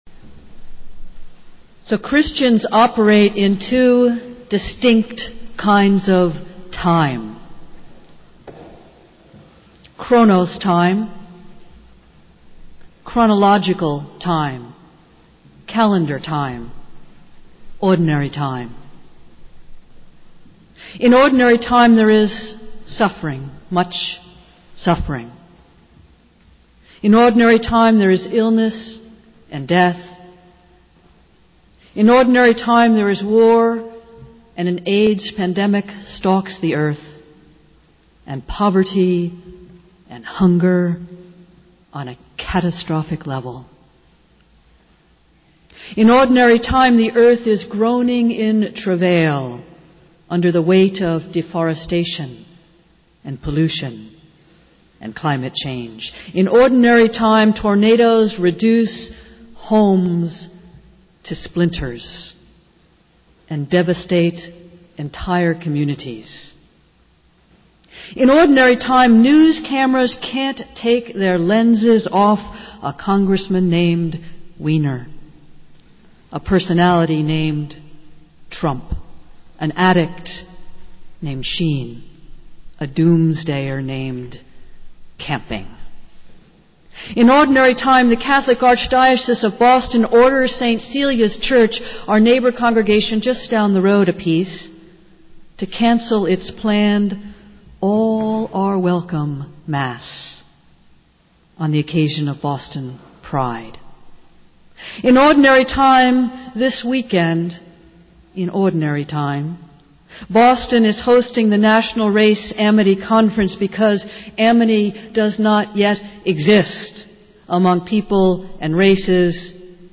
Festival Worship - Pentecost